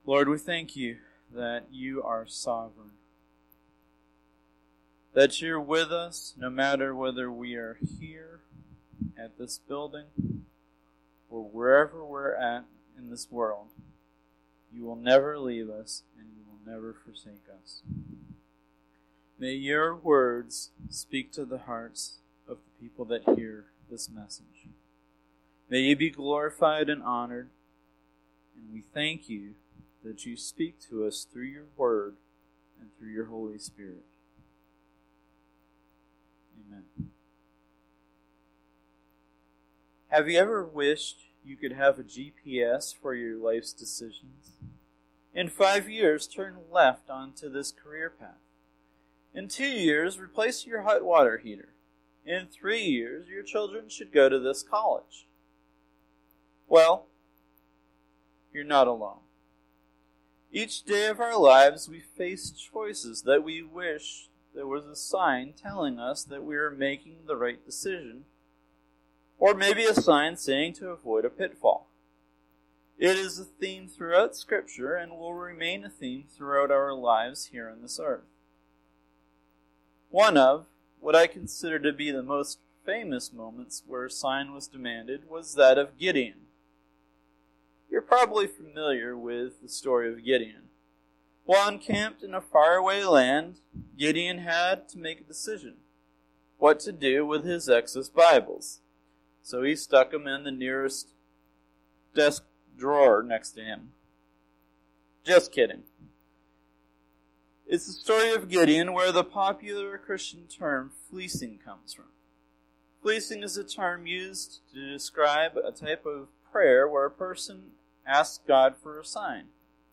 Sermon-5-3-20.mp3